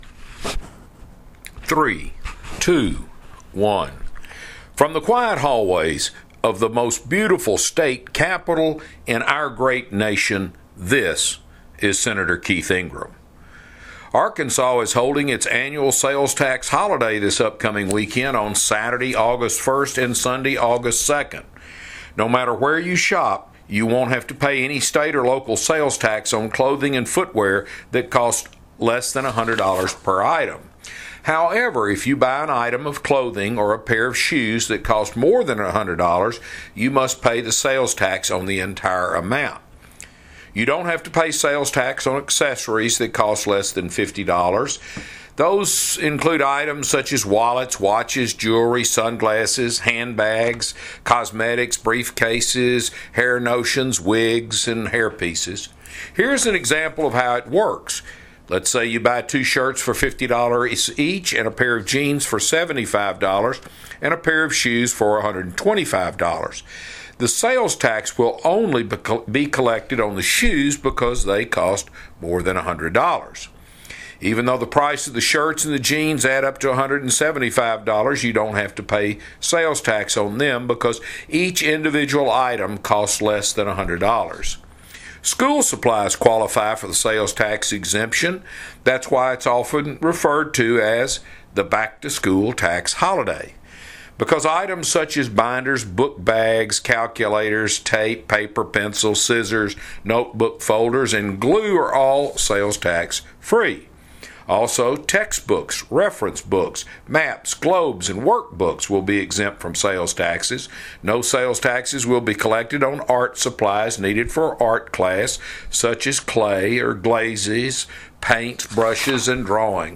Weekly Address – July 31, 2020 | 2020-07-30T18:27:01.515Z | Sen.